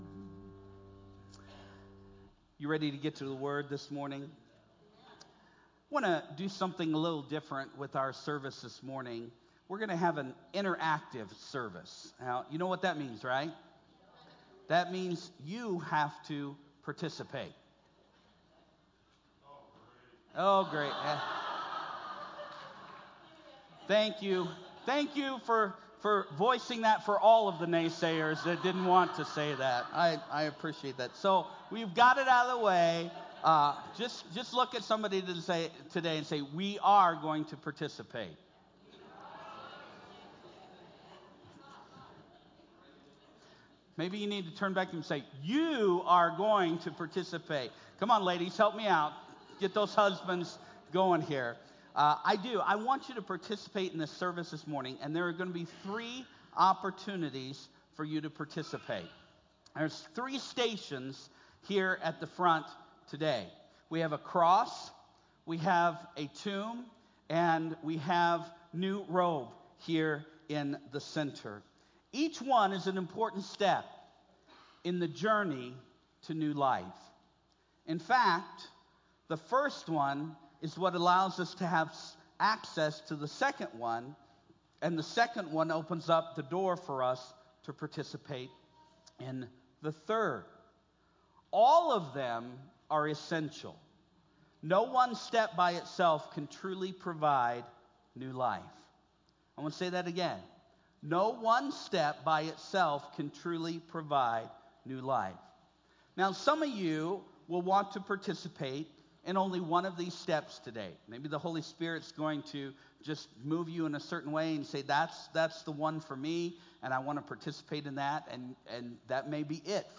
Join us for an interactive service focusing on 3 essential components of New Life